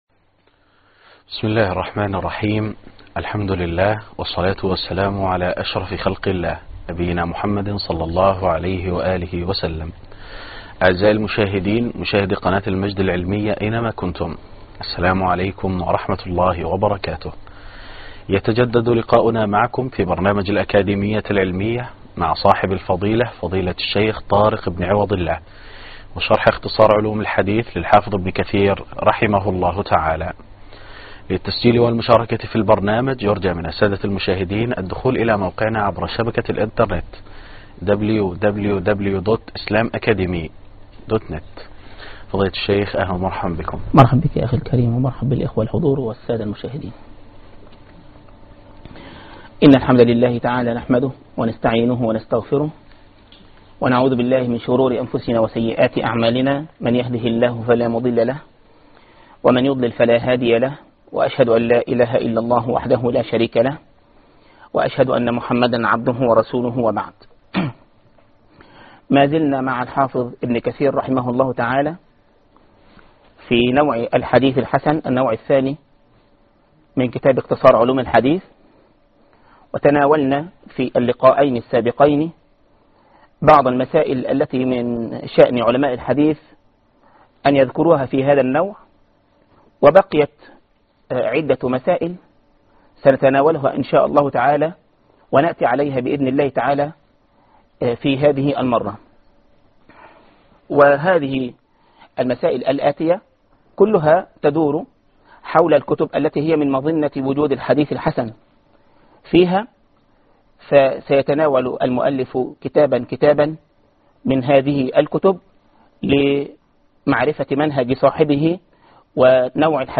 الدرس الثامن _ الترمزي أصلٌ في معرفة الحديث الحسن